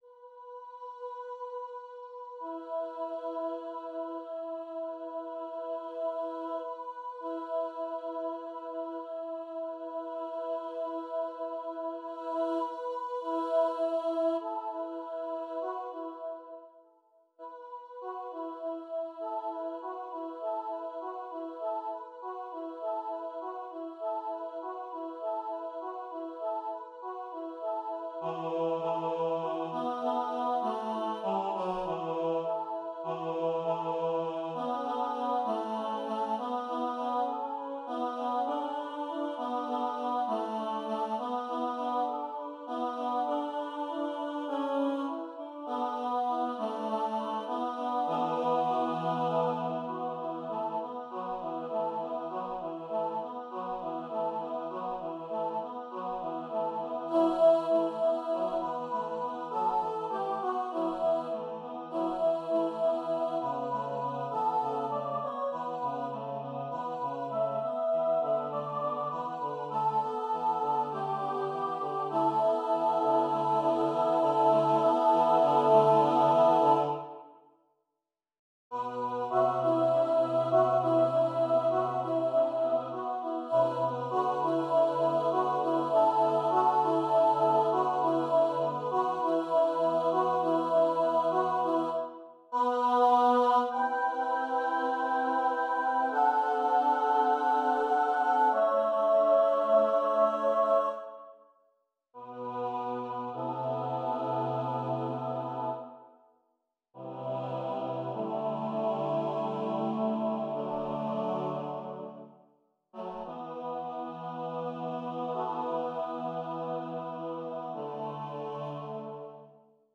SATB Choir a cappella